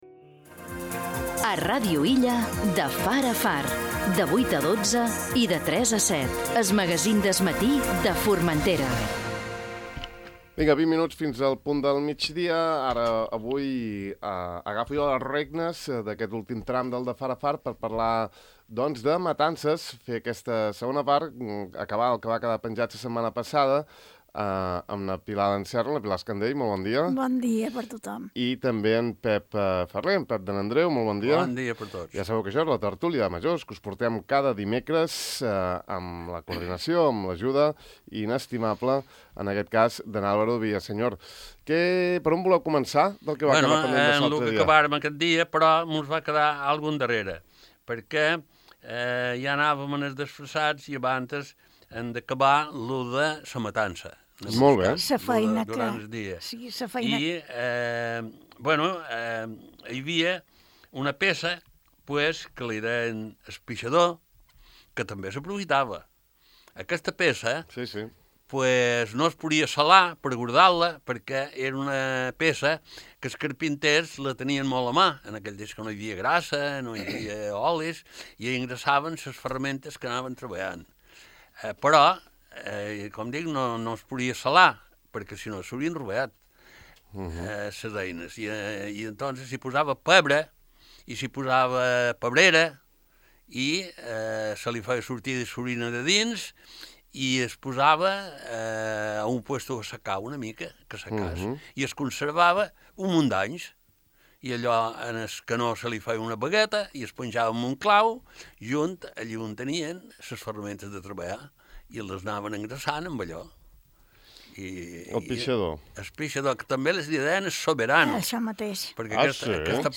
Tertúlia de majors: ses matances d'abans (segona part)